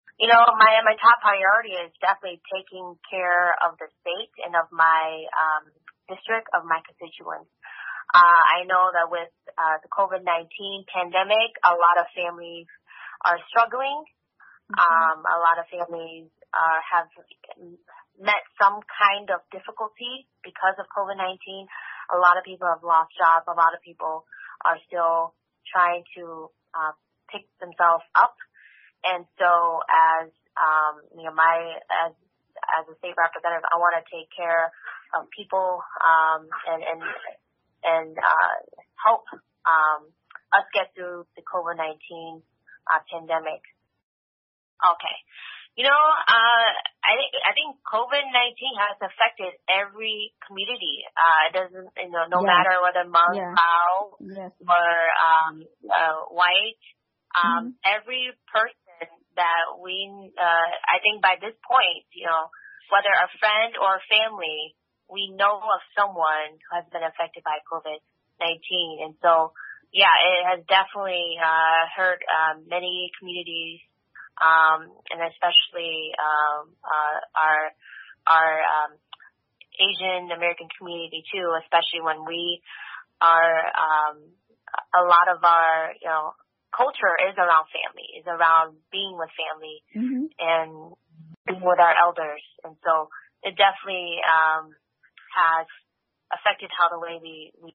ສະມາຊິກສະພາຕໍ່າຊາແມນທາ ວາງ ກ່າວກ່ຽວກັບບູລະສິດຂອງນະໂຍບາຍທ່ານນາງ